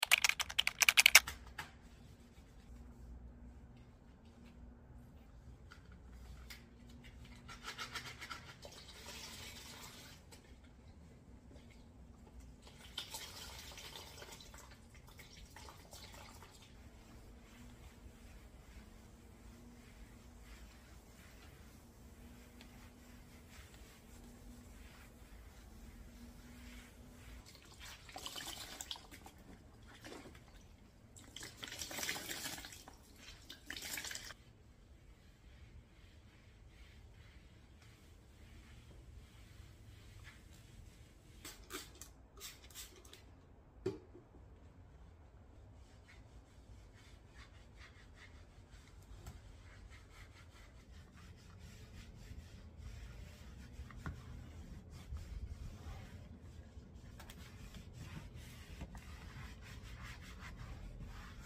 living room cleaning asmr cleantok sound effects free download